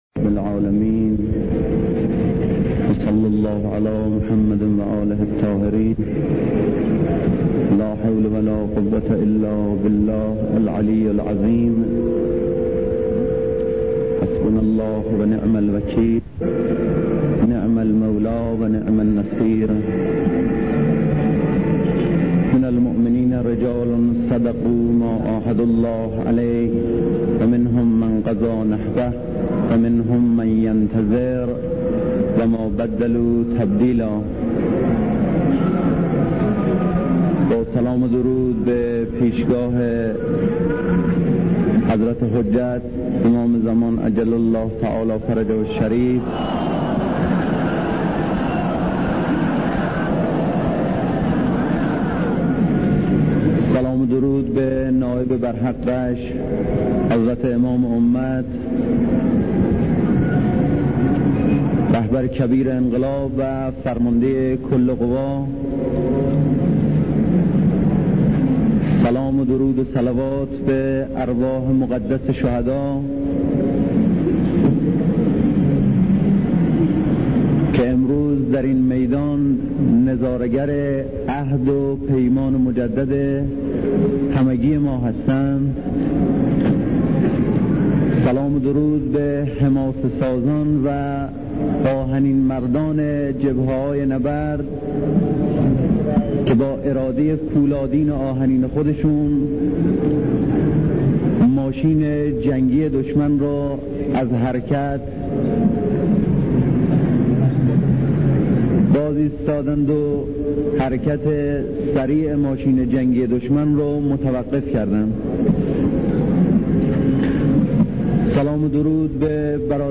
در حضور رزمندگان و خانواده های شهدا و در روز تشییع پیکرهای پاک شهدا
سخنرانی